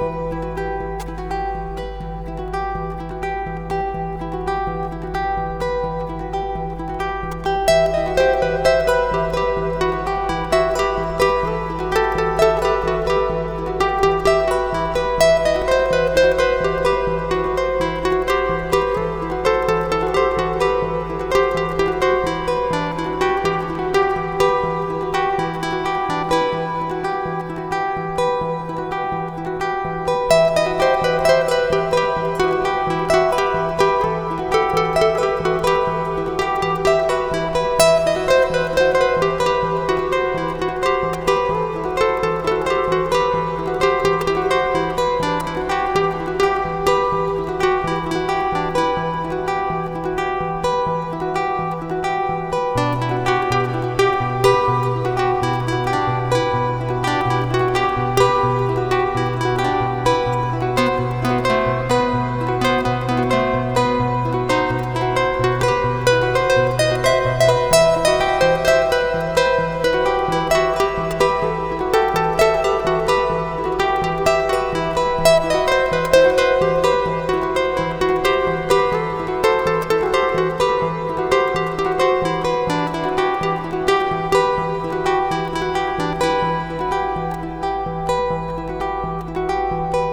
The resulting loop: Download the loop here.